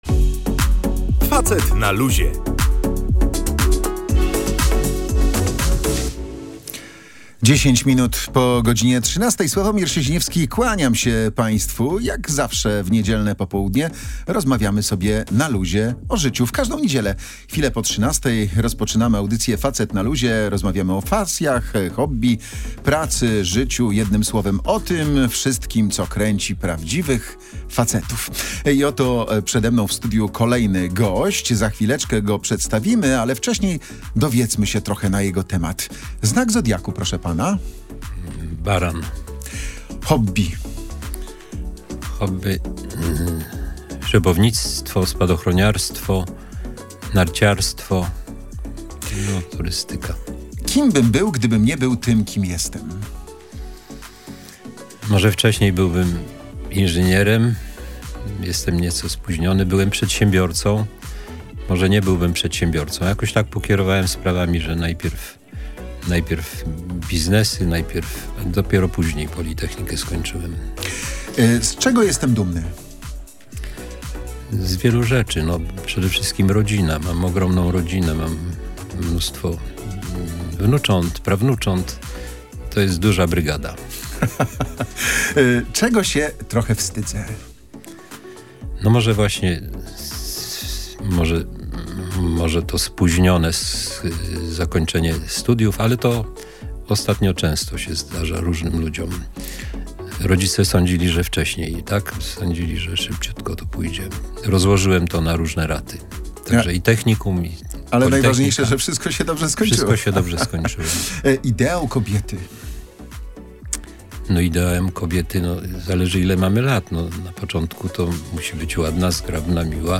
Oprócz pasji i hobby Stefan Grabski rozmawiał